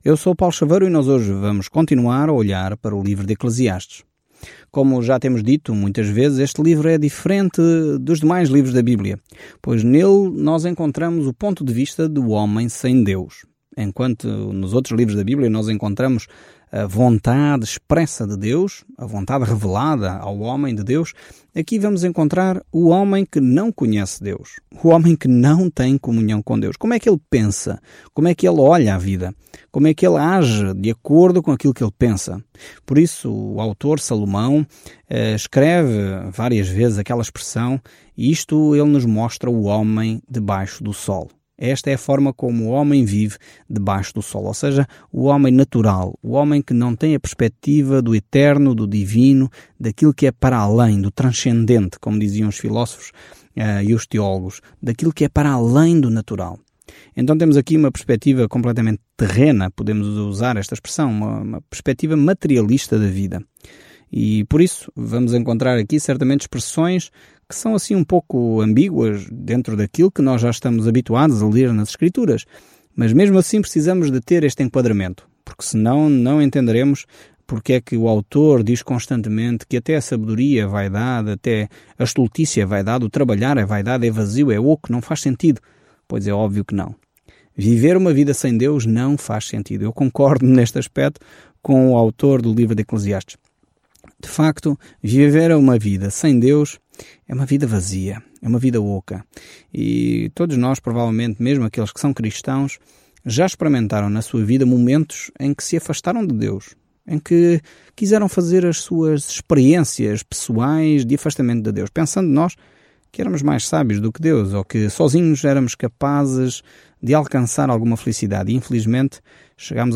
Escritura ECLESIASTES 2:22-26 ECLESIASTES 3 Dia 4 Iniciar este Plano Dia 6 Sobre este plano Eclesiastes é uma autobiografia dramática da vida de Salomão quando ele tentava ser feliz sem Deus. Viajando diariamente por Eclesiastes, você ouve o estudo em áudio e lê versículos selecionados da palavra de Deus.